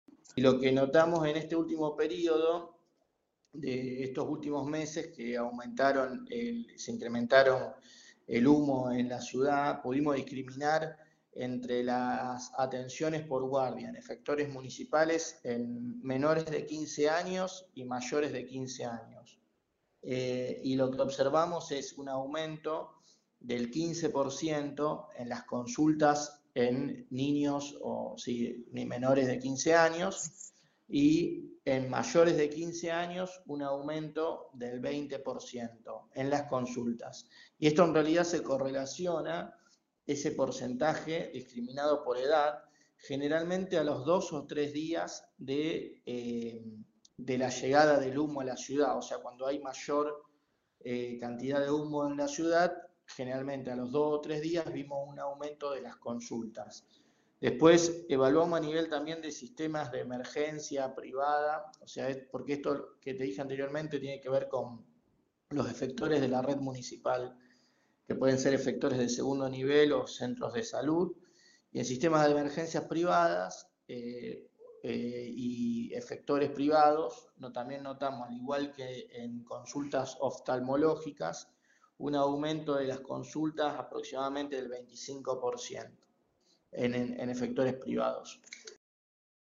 En diálogo con el móvil de Cadena 3 Rosario